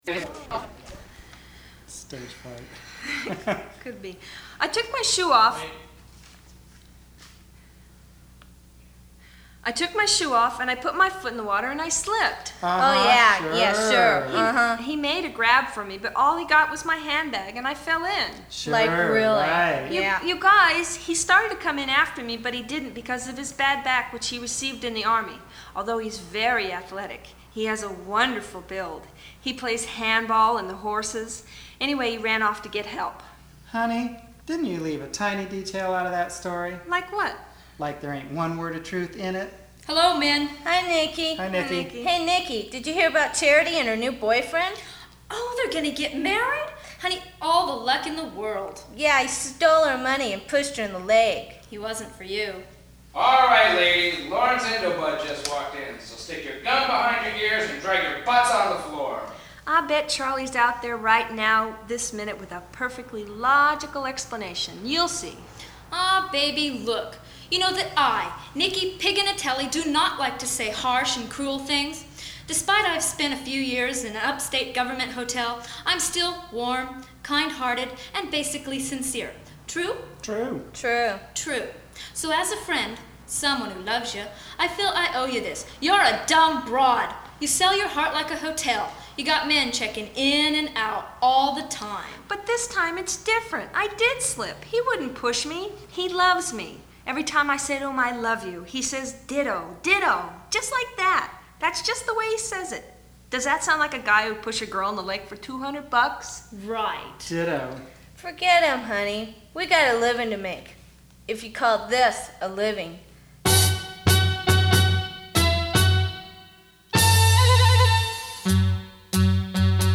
first "acting" with dialogue, then singing.
The performances are not bad, eh?